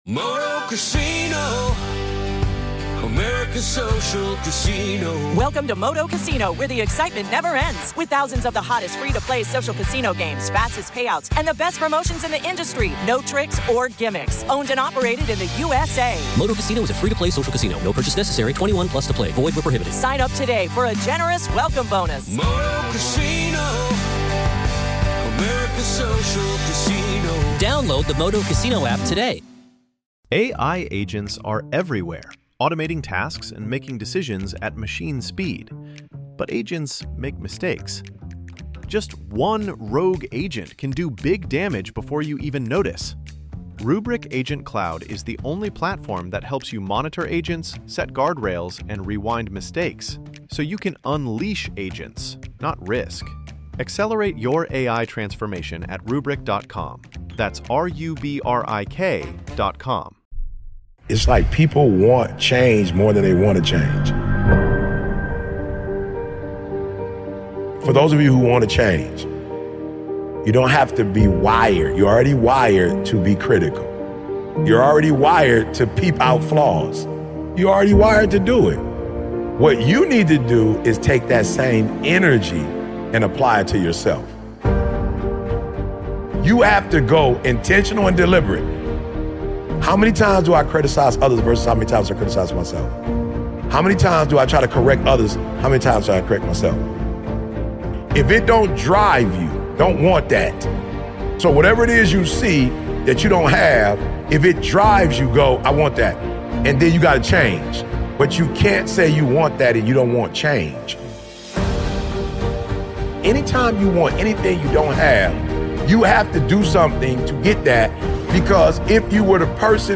Nothing changes if you don't make the change, and it starts with changing the way you see yourself. One of the Best Motivational Speeches featuring Eric Thomas.